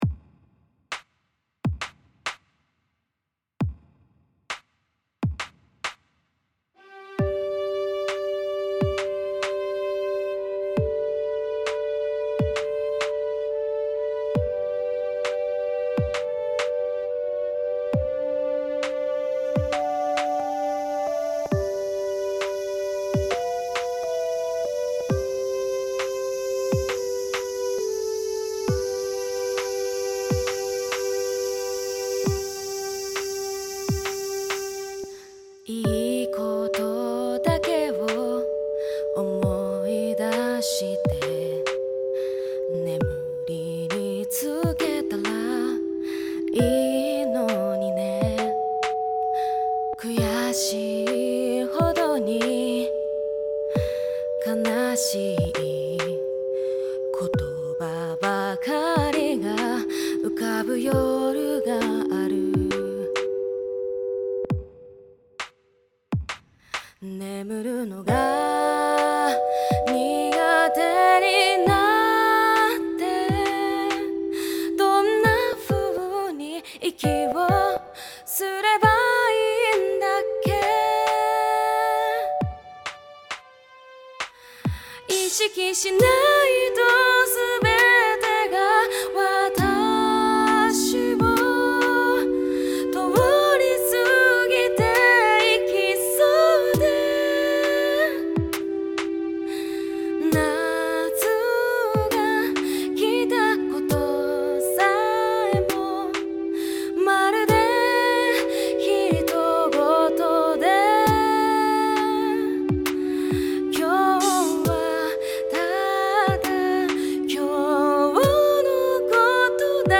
優しく穏やかで、かつソウルフルな歌声が持ち味。